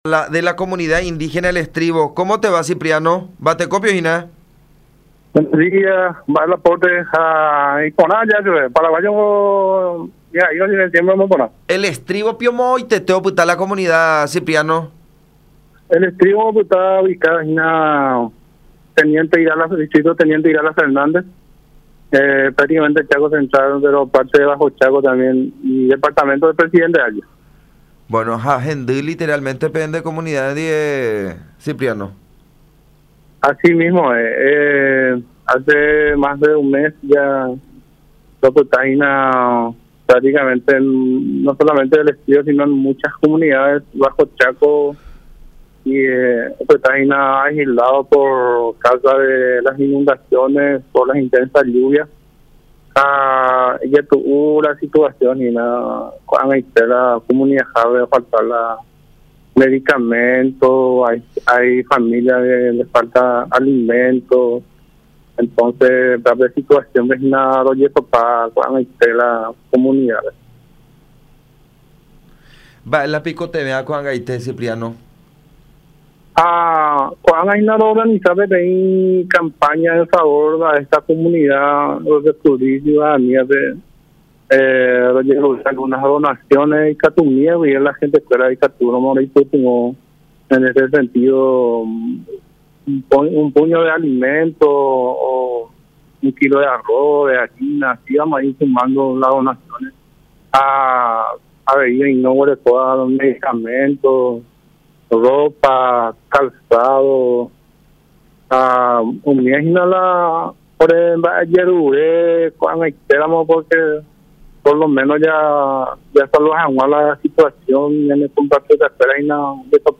en comunicación con La Unión.